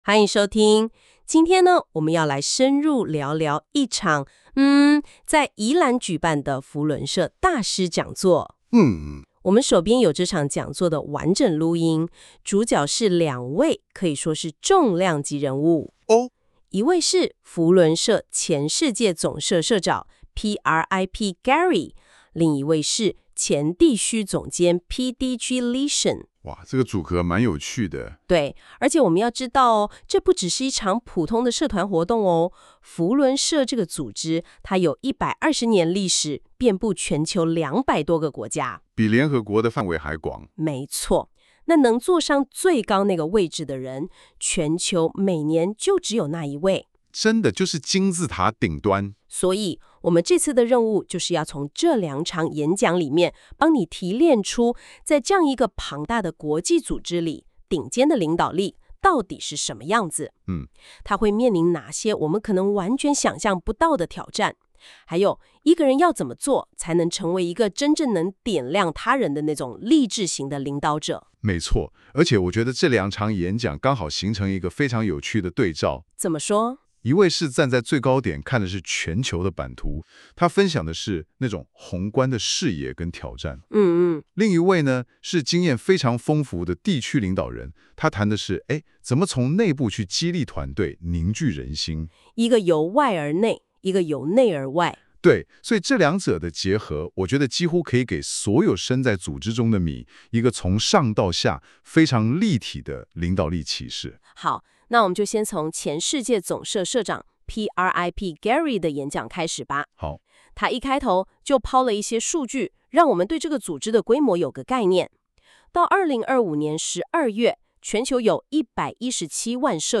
大師講座 | 光耀扶輪故事館